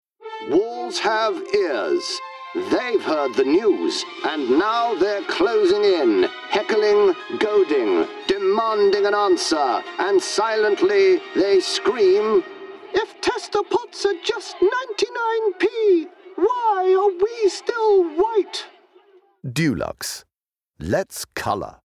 Murray has a deep, expressive and brilliantly theatrical tone to his voice.
• Male
Heightened RP BBC Broadcaster.